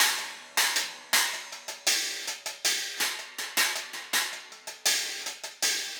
Cymbal and Hi Hat 01.wav